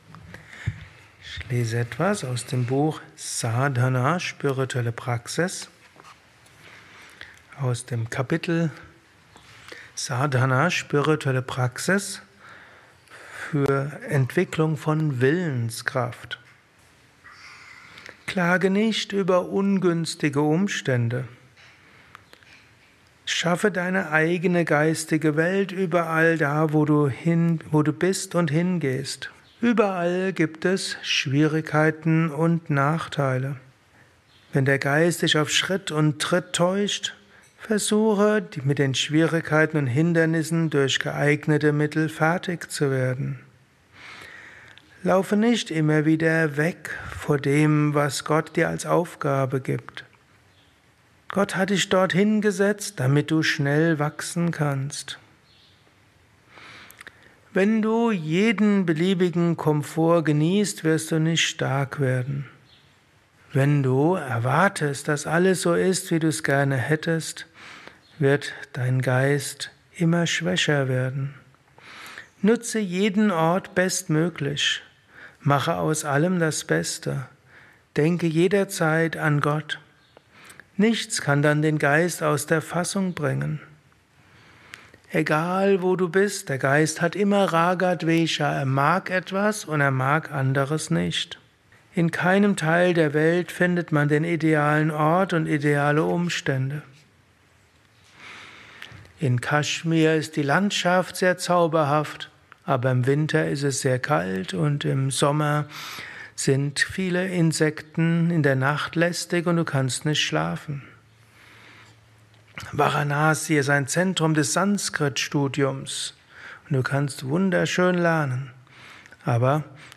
Kurzvorträge
Satsangs gehalten nach einer Meditation im Yoga Vidya Ashram Bad